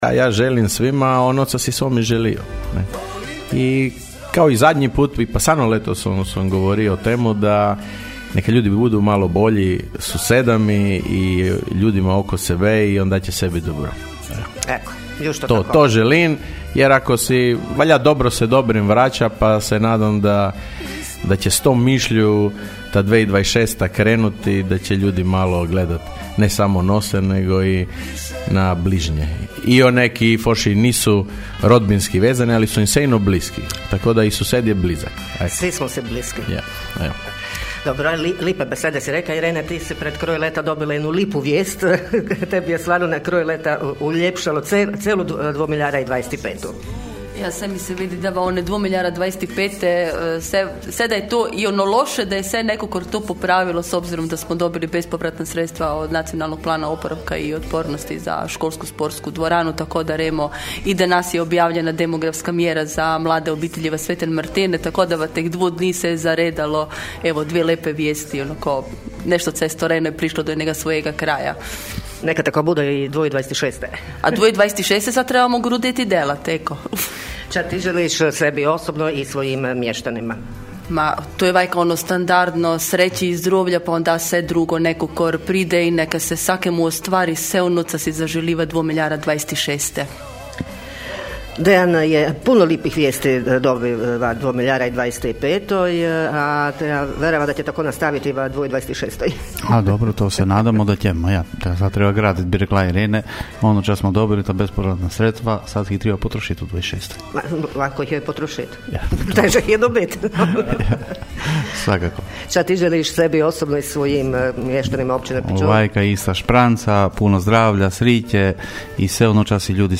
U našem novogodišnjem programu čelnici Labinštine bili su izuzetno dobro raspoloženi.